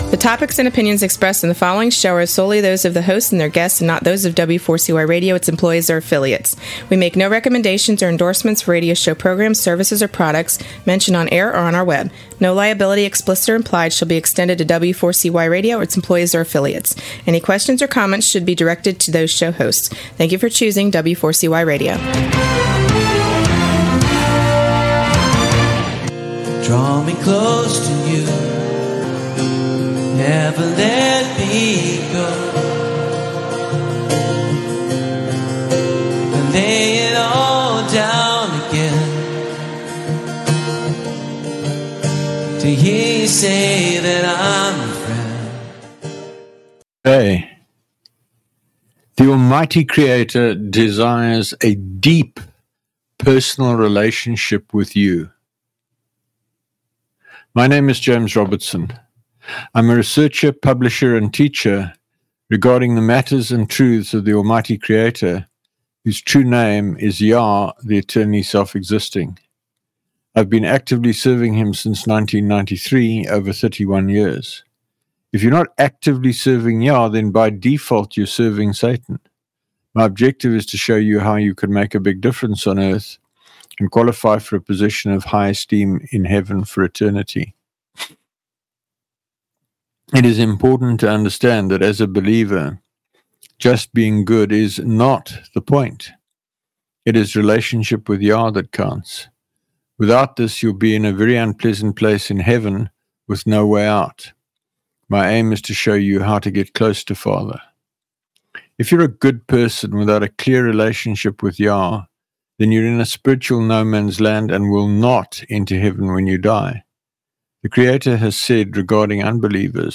I read from MattihYahoo and offer commentary.